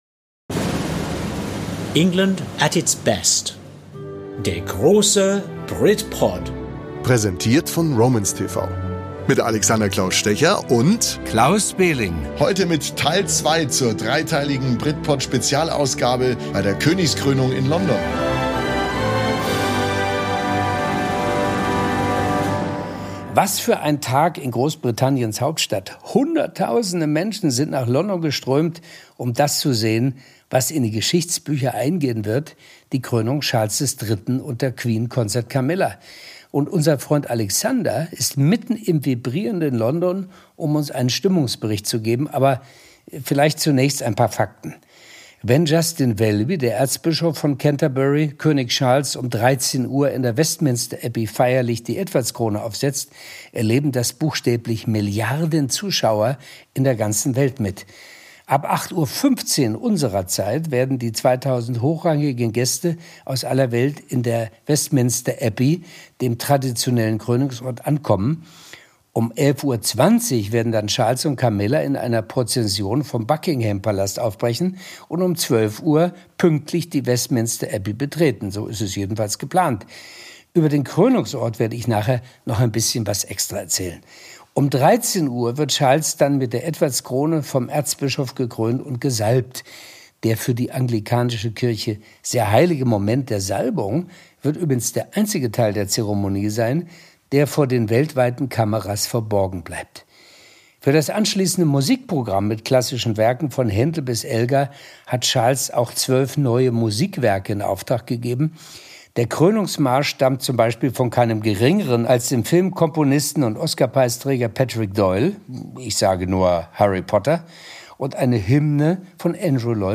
Dank Akkreditierung ist BRITPOD live dabei: Vor den Toren der Westminster Abbey und am Eingang des Buckingham Palace.